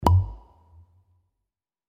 Звук щелчка при открытии крышки бутылки